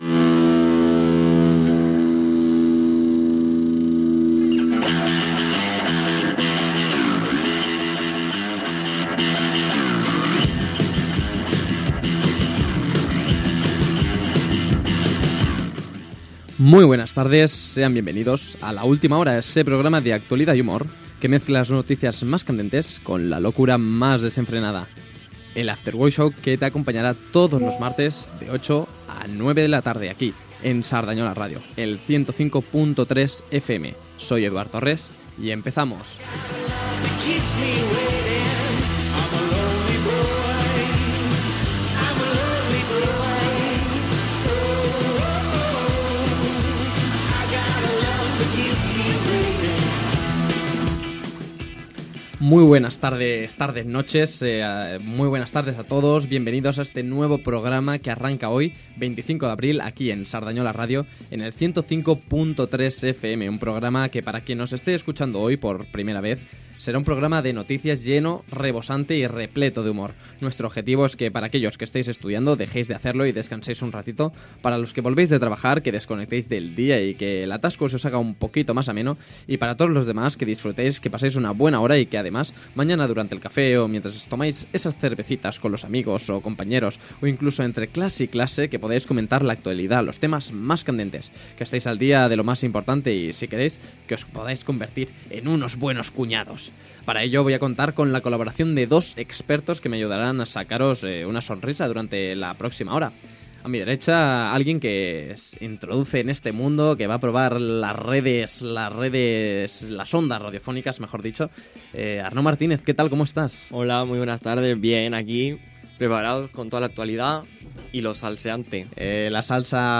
Inici del programa d'humor i actualitat.
Entreteniment